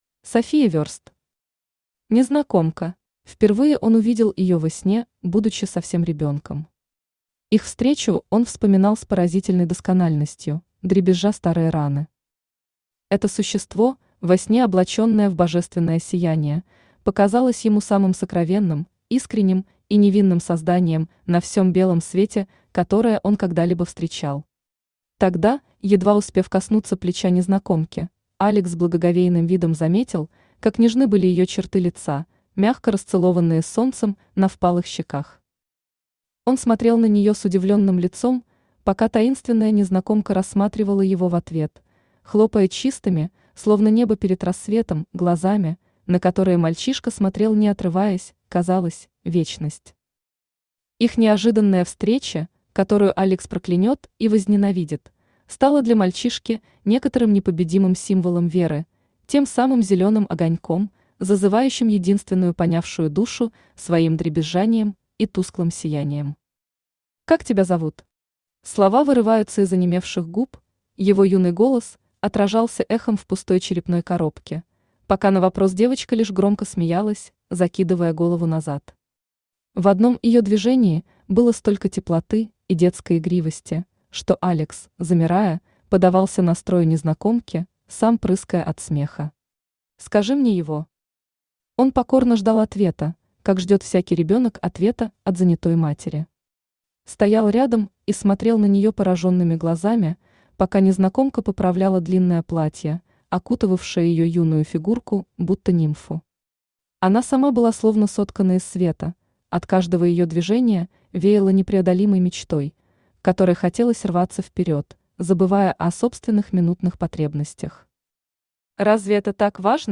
Aудиокнига Незнакомка Автор София Верст Читает аудиокнигу Авточтец ЛитРес.